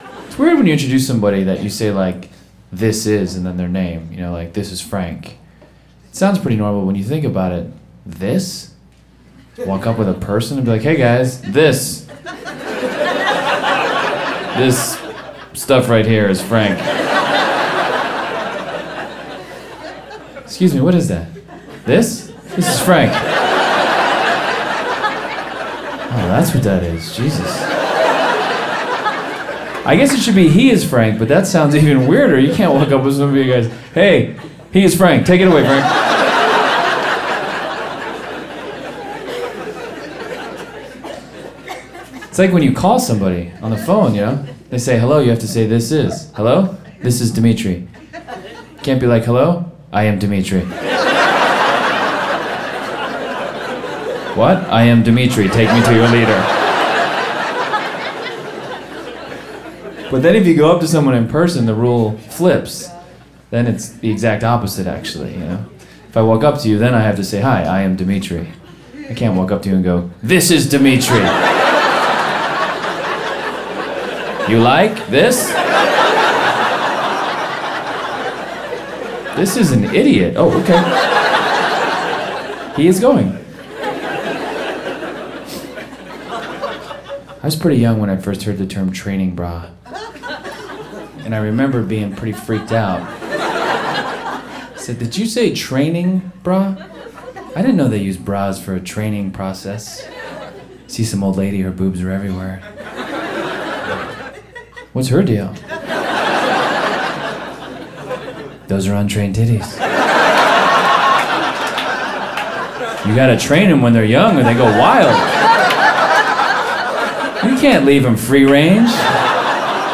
Demetri Martin is a famous comedian in the United States.
Usually a dry comedian says jokes in a monotone voice.
In the following clip you will find a comedy bit by Demetri Martin where he talks about the funny yet very bizarre way in which English speakers introduce themselves.